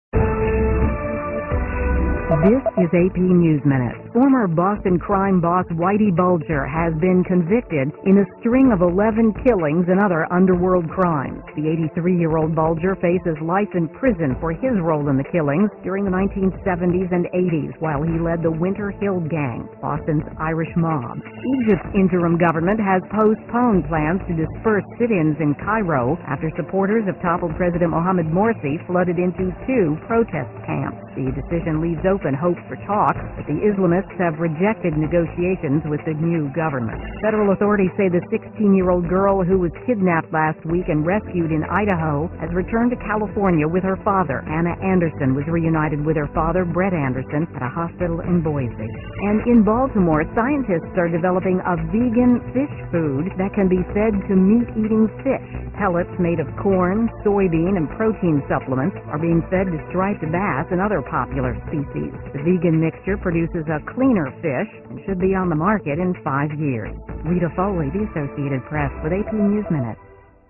在线英语听力室美联社新闻一分钟 AP 2013-08-15的听力文件下载,美联社新闻一分钟2013,英语听力,英语新闻,英语MP3 由美联社编辑的一分钟国际电视新闻，报道每天发生的重大国际事件。电视新闻片长一分钟，一般包括五个小段，简明扼要，语言规范，便于大家快速了解世界大事。